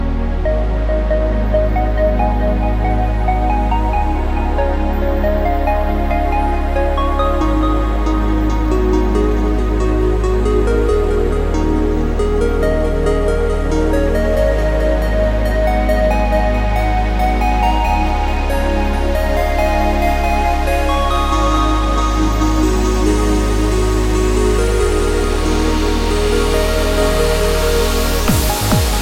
Electronica
dance , tune , electro , house ,